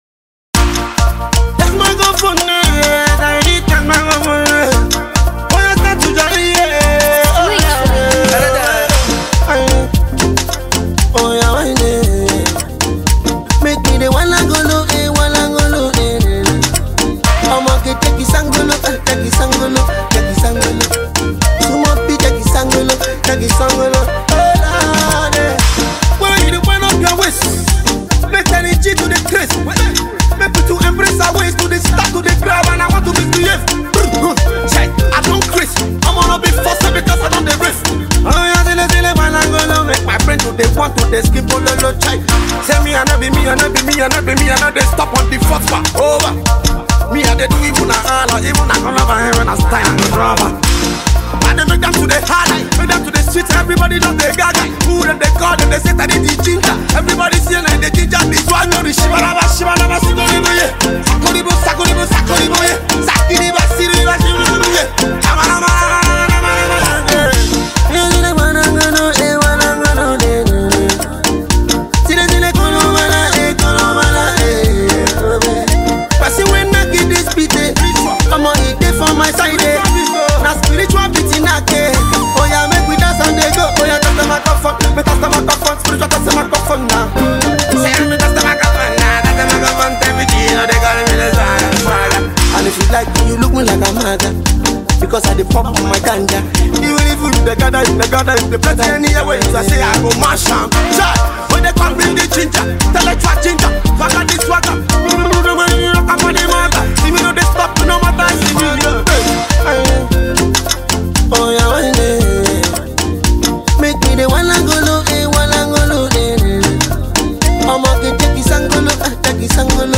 catchy tune